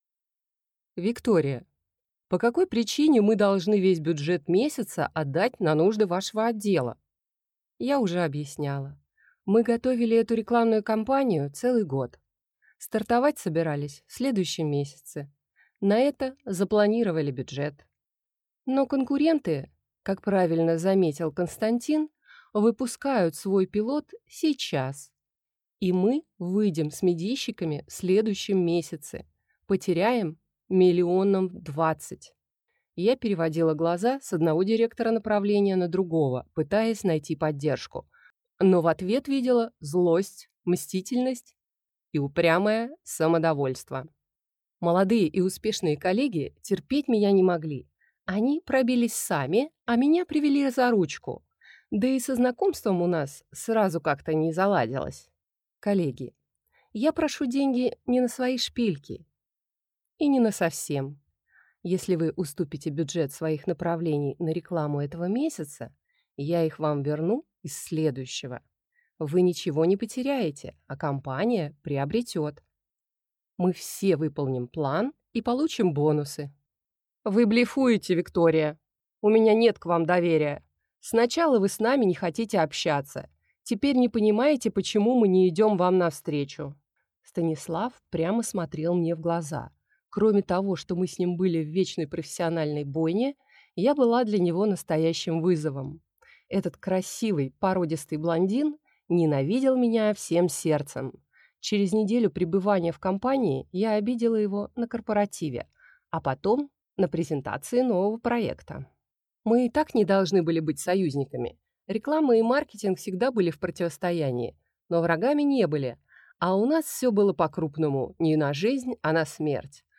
Аудиокнига Карты на стол | Библиотека аудиокниг
Прослушать и бесплатно скачать фрагмент аудиокниги